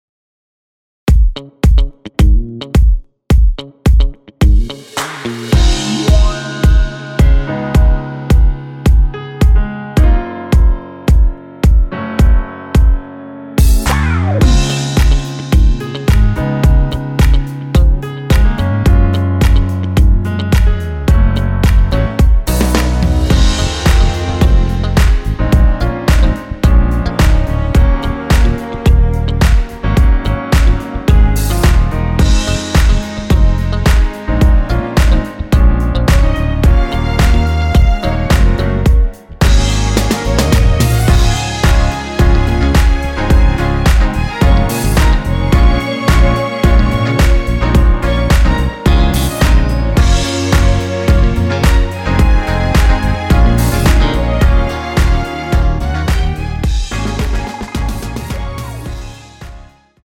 원키에서(-3)내린 MR입니다.
Db
앞부분30초, 뒷부분30초씩 편집해서 올려 드리고 있습니다.
중간에 음이 끈어지고 다시 나오는 이유는